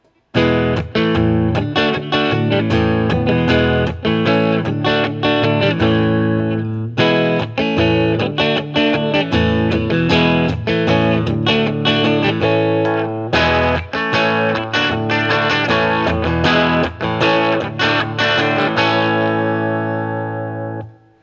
Every sound sample cycles from the neck pickup, to both, to the bridge pickup.
Open Chords #2
[/dropshadowbox]For these recordings I used my normal Axe-FX Ultra setup through the QSC K12 speaker recorded into my trusty Olympus LS-10.